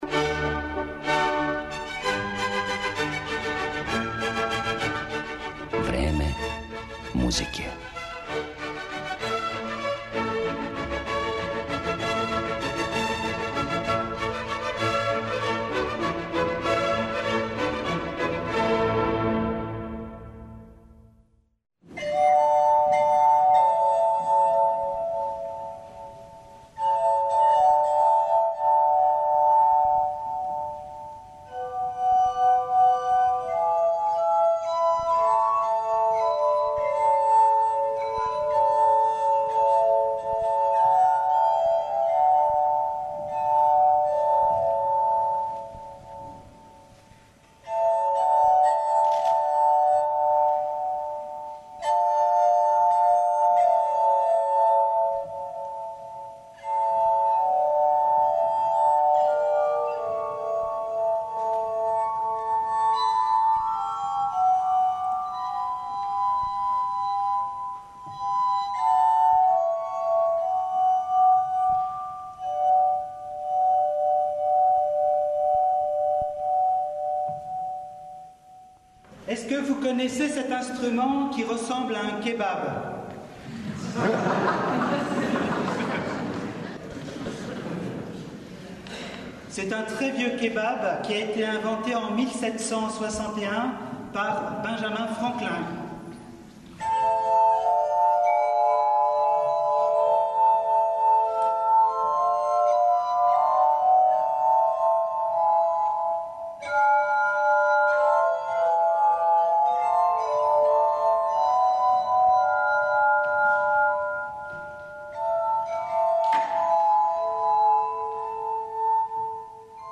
Слушаћете инструмент који је настао као плод инспирације свирања на чашама, гласхармонику и прави мушки сопран.